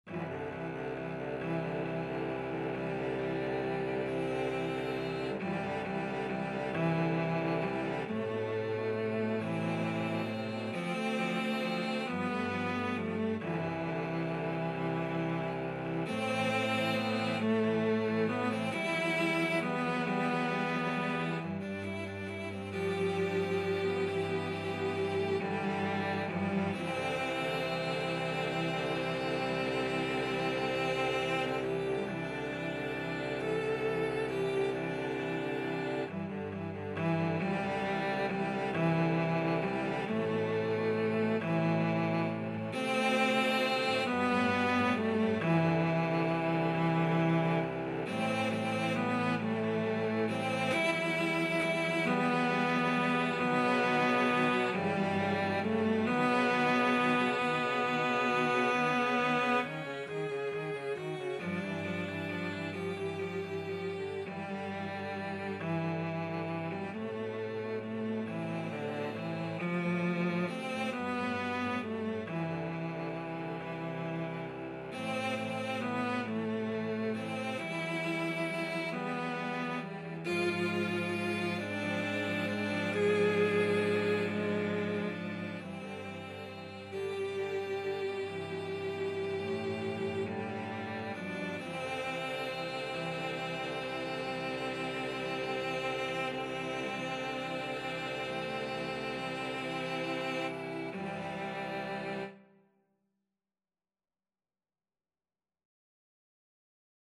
6/8 (View more 6/8 Music)
Slowly .=45
Cello Quartet  (View more Intermediate Cello Quartet Music)
Classical (View more Classical Cello Quartet Music)